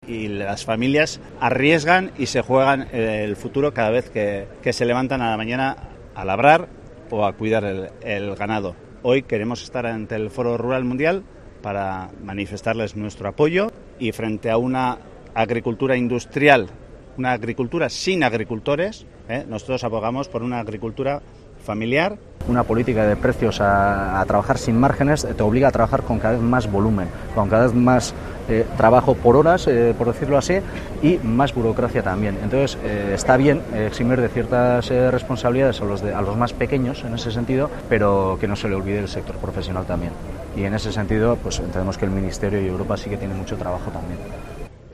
portavoces de los sindicatos agrarios frente al Europa